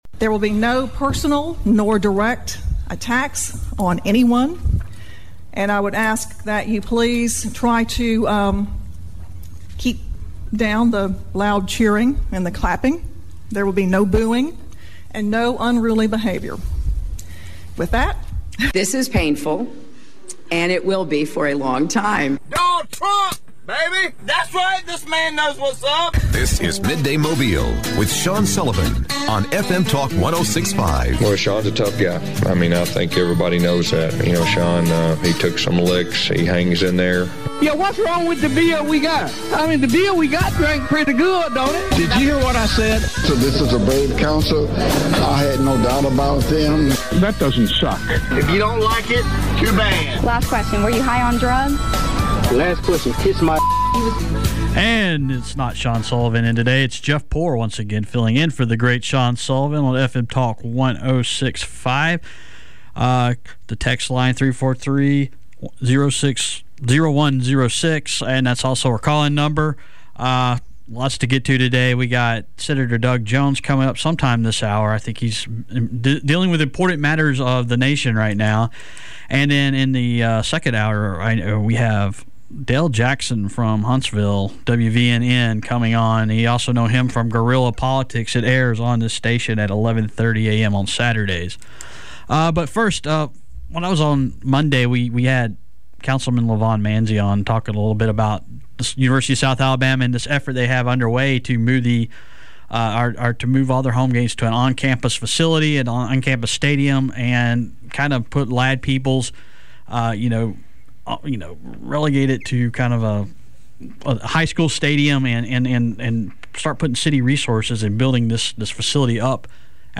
Doug Jones interviewed